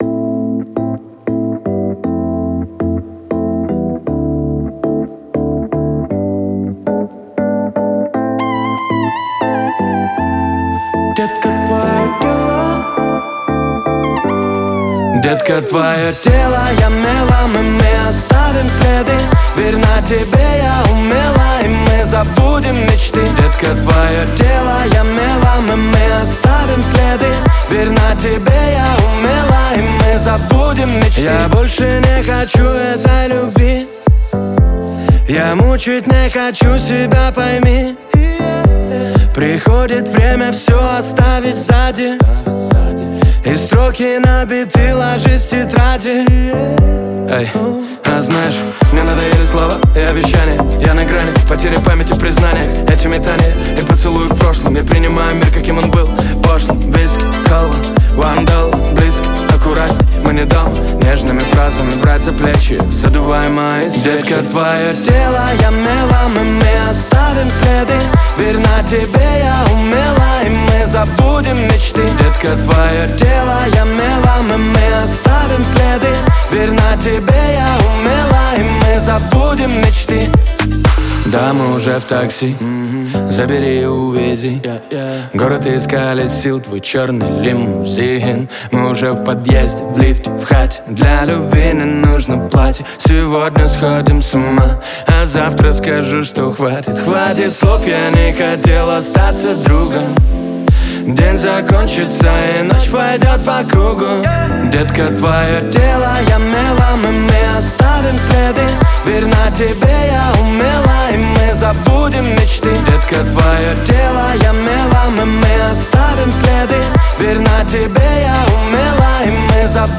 зажигательная песня в стиле поп-музыки